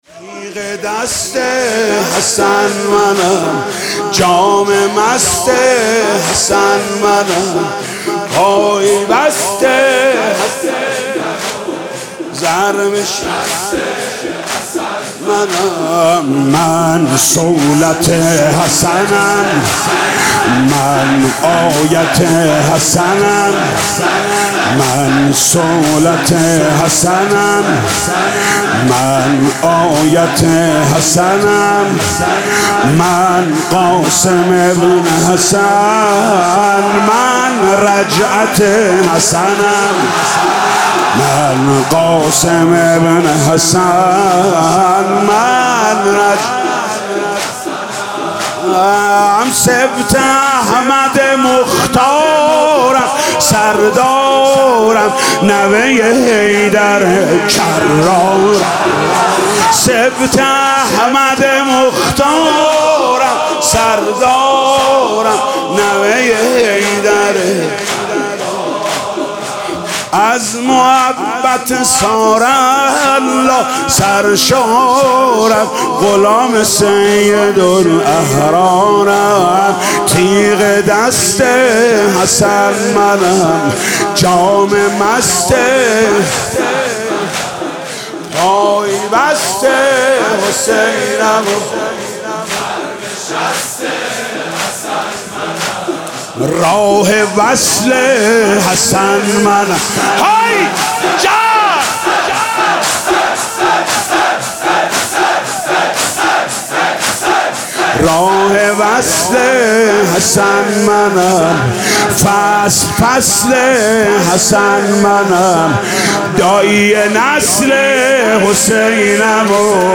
زمینه | تیغ دست حسن، منم - محمود کریمی (فیلم، صوت، متن)
دانلود نوحه «تیغ دست حسن، منم» با نوای دلنشین حاج محمود کریمی به همراه متن روضه